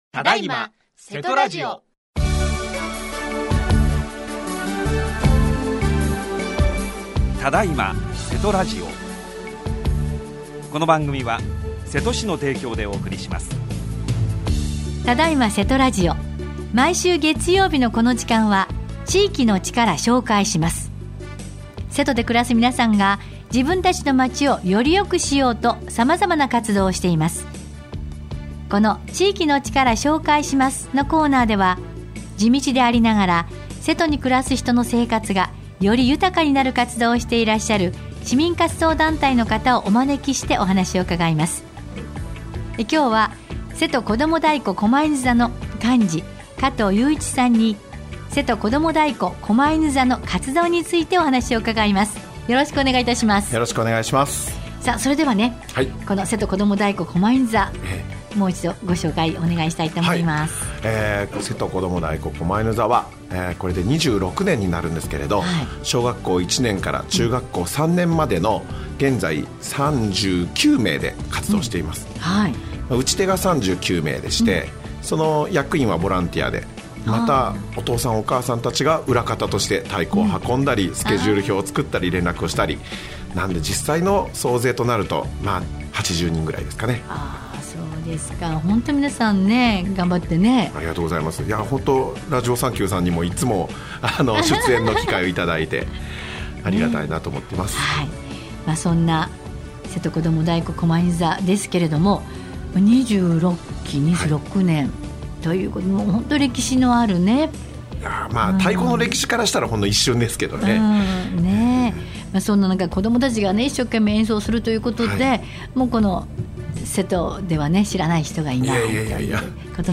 27年12月14日（月） 毎週月曜日のこの時間は、〝地域の力 紹介します〝 このコーナーでは、地道でありながら、 瀬戸に暮らす人の生活がより豊かになる活動をしていらっしゃる 市民活動団体の方をお招きしてお話を伺います。